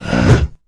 spawners_mobs_mummy_attack.1.ogg